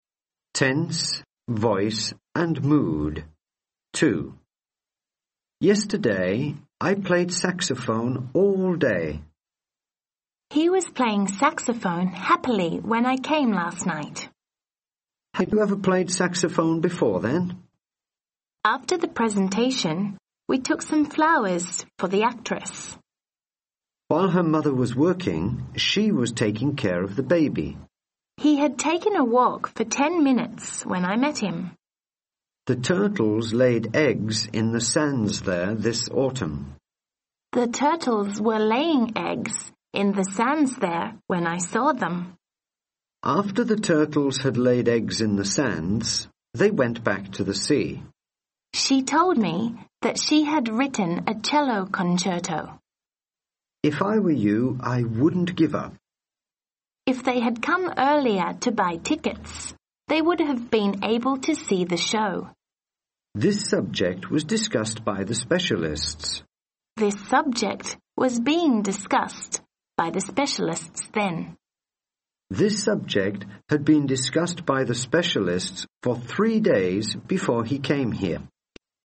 Dialogue：Tense, Voice and Mood Ⅱ